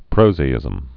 (prōzā-ĭzəm)